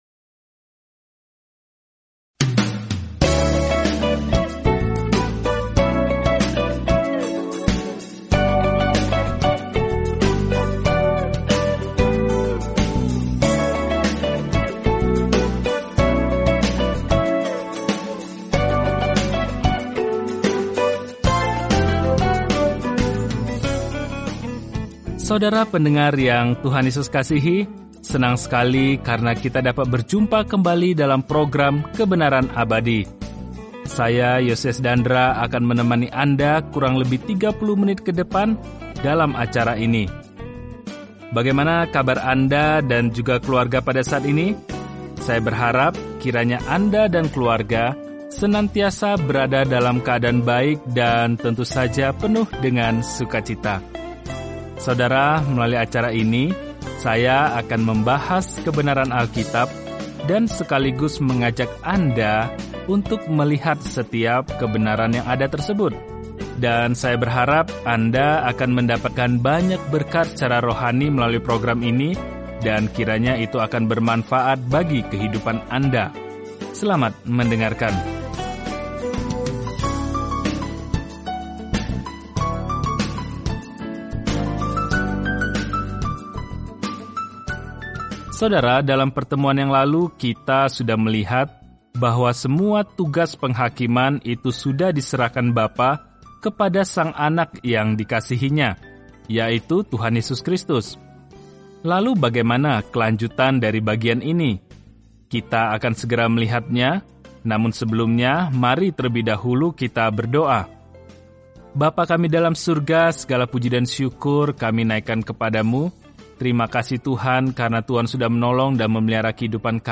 Firman Tuhan, Alkitab Yohanes 5:25-47 Hari 9 Mulai Rencana ini Hari 11 Tentang Rencana ini Kabar baik yang dijelaskan Yohanes unik dibandingkan Injil lainnya dan berfokus pada mengapa kita hendaknya percaya kepada Yesus Kristus dan bagaimana memiliki kehidupan dalam nama ini. Telusuri Yohanes setiap hari sambil mendengarkan pelajaran audio dan membaca ayat-ayat tertentu dari firman Tuhan.